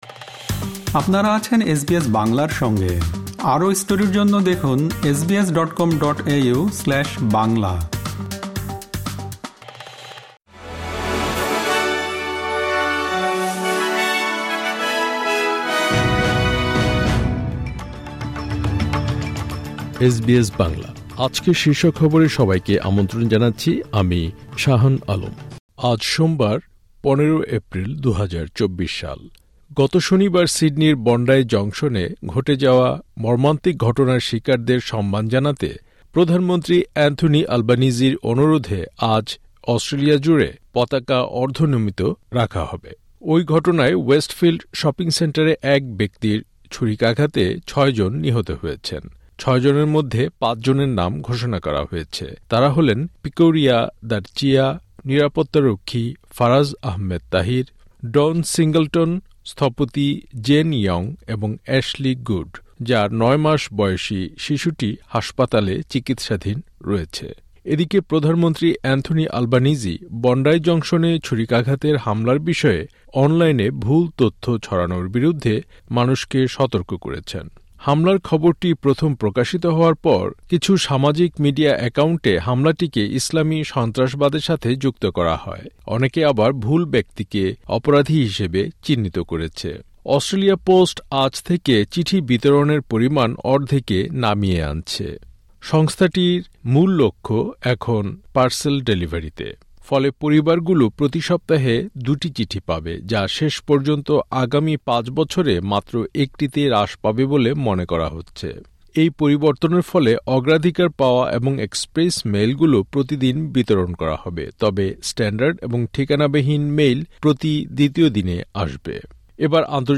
আজকের শীর্ষ খবর গত শনিবার সিডনির বন্ডাই জংশনে ঘটে যাওয়া মর্মান্তিক ঘটনার শিকারদের সম্মান জানাতে প্রধানমন্ত্রী অ্যান্থনি আলবানিজির অনুরোধে আজ অস্ট্রেলিয়া জুড়ে পতাকা অর্ধনমিত রাখা হবে। প্রধানমন্ত্রী অ্যান্থনি আলবানিজি বন্ডাই জংশনে ছুরিকাঘাতের হামলার বিষয়ে অনলাইনে ভুল তথ্য ছড়ানোর বিরুদ্ধে মানুষকে সতর্ক করেছেন। অস্ট্রেলিয়া পোস্ট আজ থেকে চিঠি বিতরণের পরিমান অর্ধেকে নামিয়ে আনছে।